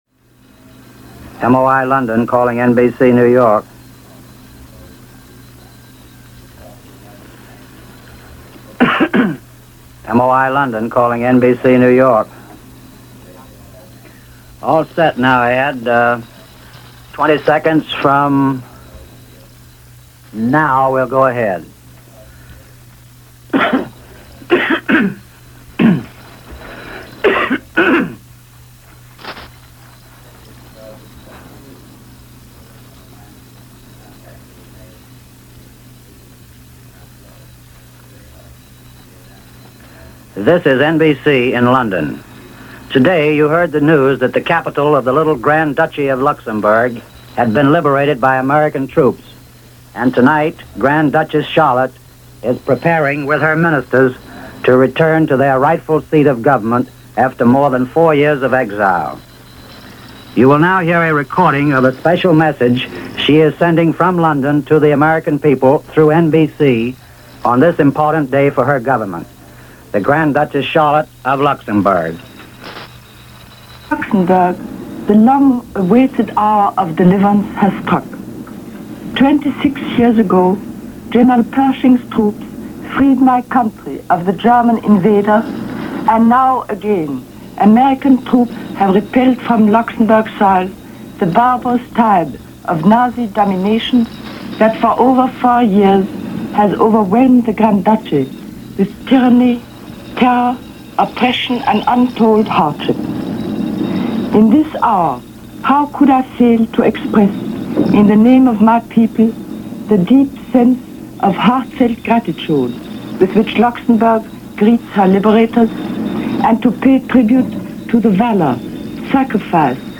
Luxembourg Liberated - Allies On German Soil - September 11, 1944 - reports over closed-circuit shortwave lines.
A sample of this difficulty (and just one of the difficulties in covering a war at the time) is this report featuring an address to the American people by Grand Duchess Charlotte on the occasion of Luxembourg now back in Allied hands.